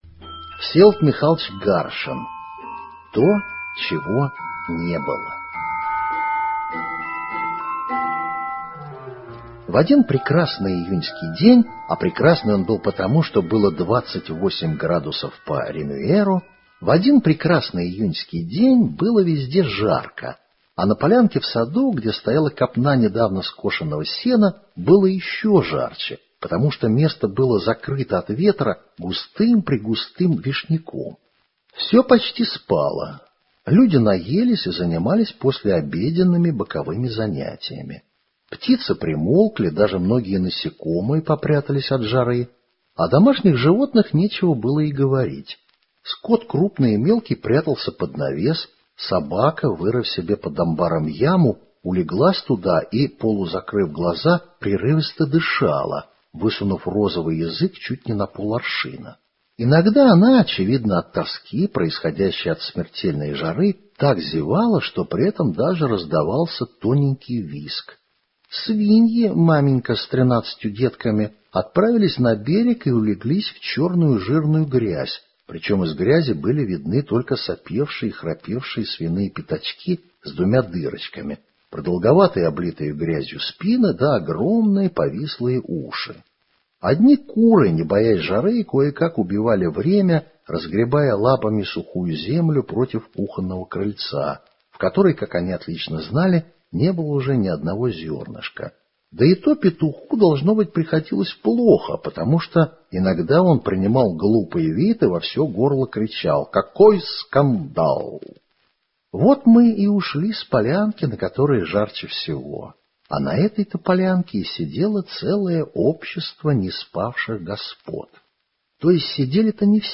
То, чего не было - аудиосказка Всеволода Гаршина - слушать онлайн